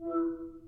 Download Facetime sound effect for free.